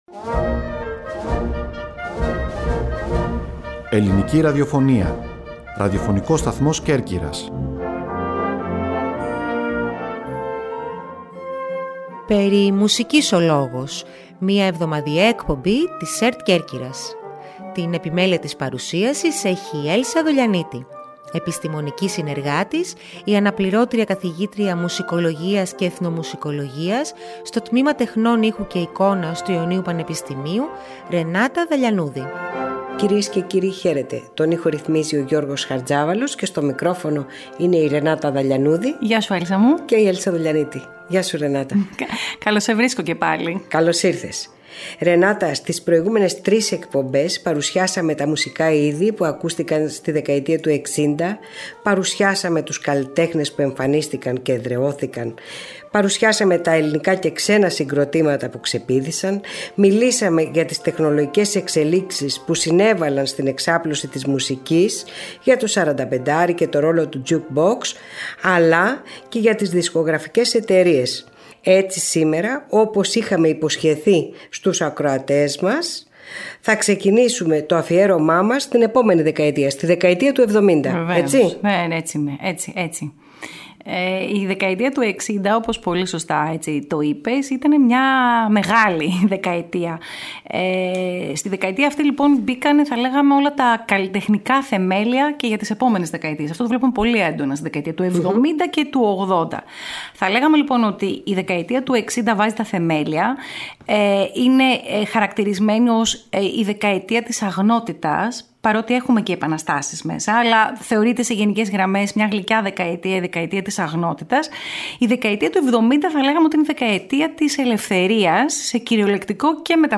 Στην εκπομπή αυτή ακούμε τραγούδια γνωστών καλλιτεχνών που άνθισαν τη δεκαετία αυτή, μία εποχή που σηματοδοτεί την απελευθέρωση σε πολλαπλά επίπεδα.